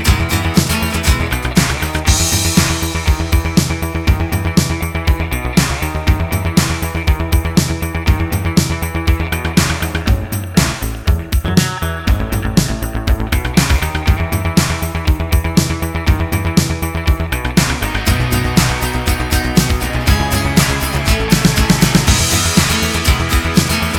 Minus All Guitars Pop (1990s) 2:56 Buy £1.50